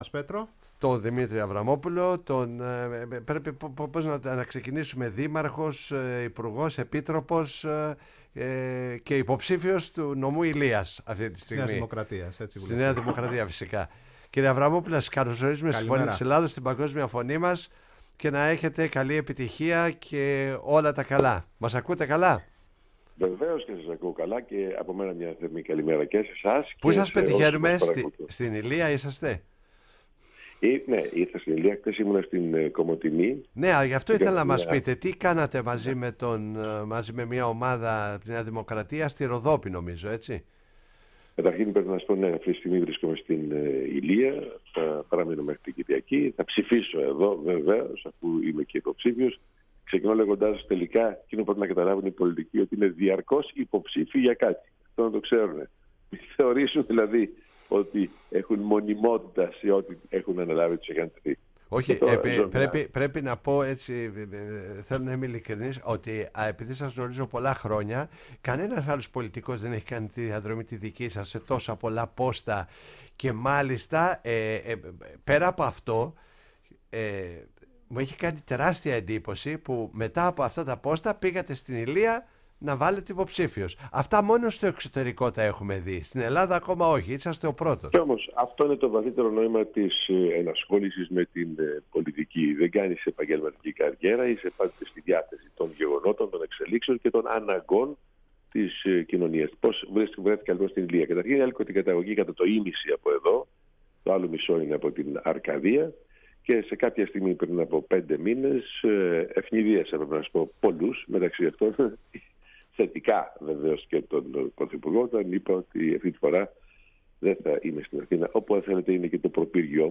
στην εκπομπή “Η Παγκόσμια Φωνή μας” στο ραδιόφωνο της Φωνής της Ελλάδας